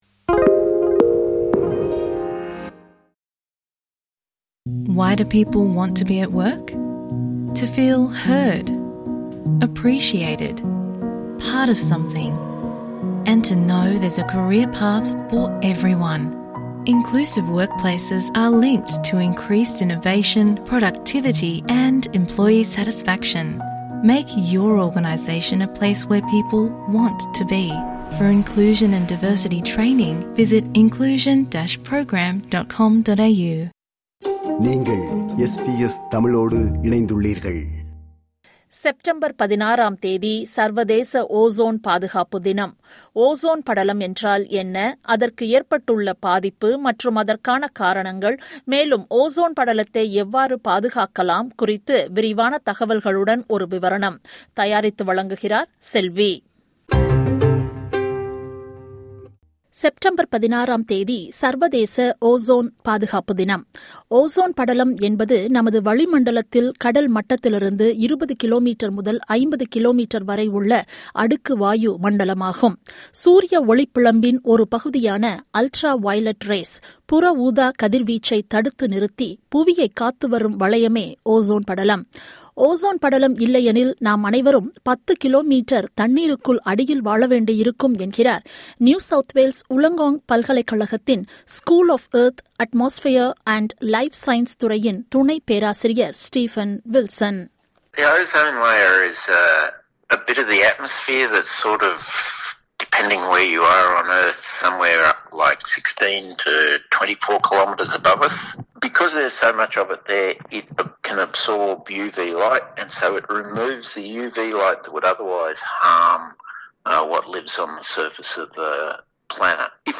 செப்டம்பர் 16ஆம் தேதி - சர்வதேச ozone பாதுகாப்பு தினம். Ozone படலம் என்றால் என்ன? அதற்கு ஏற்பட்டுள்ள பாதிப்பு மற்றும் அதற்கான காரணங்கள் மேலும் ozone படலத்தை எவ்வாறு பாதுகாக்கலாம் குறித்து விரிவான தகவல்களுடன் ஒரு விவரணம்.